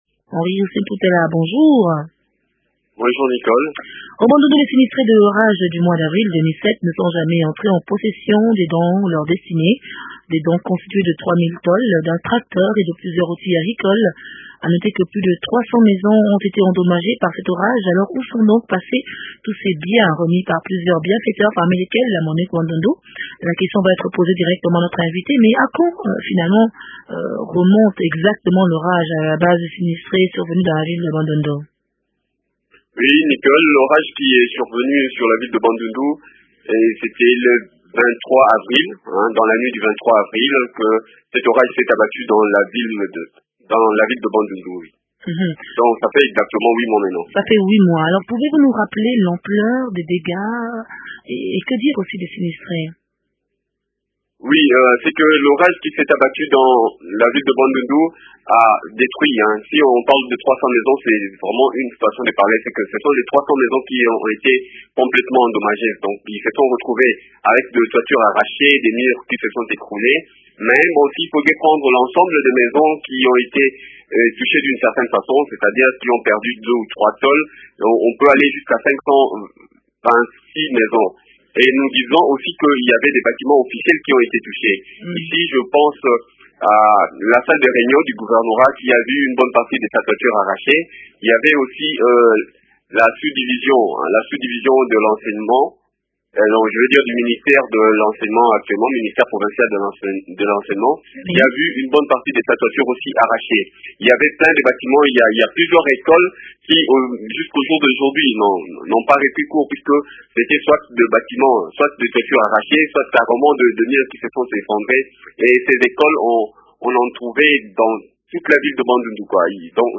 Richard Ndambo Wolang, gouverneur du Bandundu, s’explique au micro